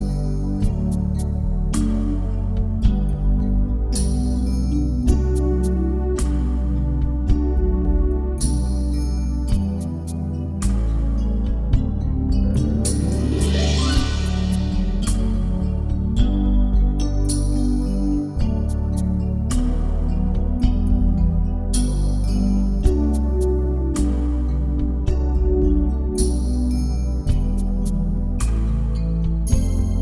Cut Down Version with Whistling Soundtracks 1:52 Buy £1.50